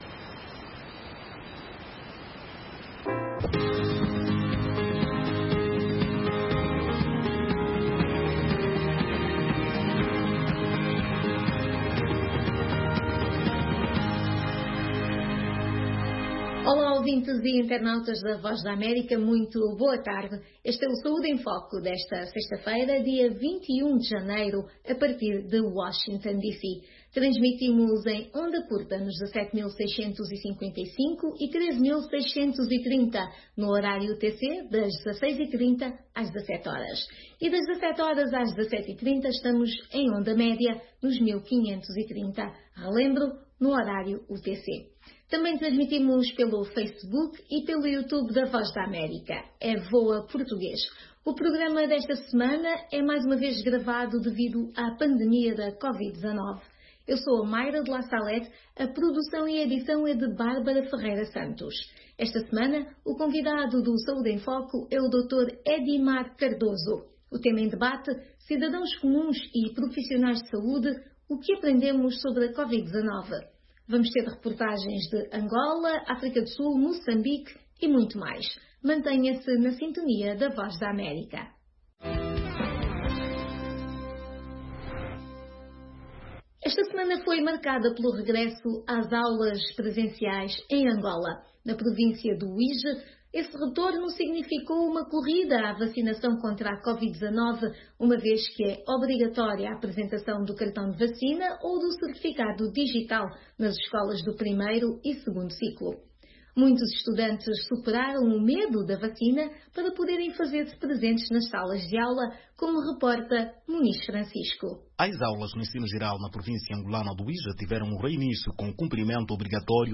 O programa Angola Saúde em Foco é transmitido às sextas-feiras às 17h30 (hora de Angola). Todas as semanas angolanos de Cabinda ao Cunene conversam com todo o país e com um convidado especial sobre os seus anseios e inquetações no campo social e da saúde.